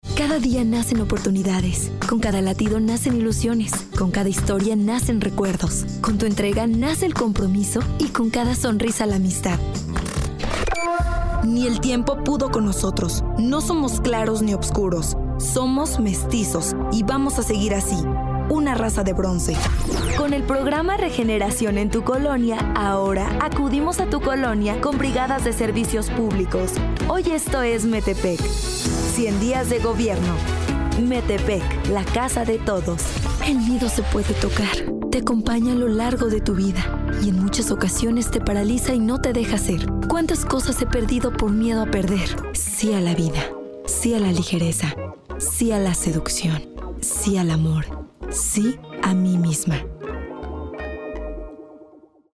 Locutora
DEMO SIN MARCAS COMERCIALES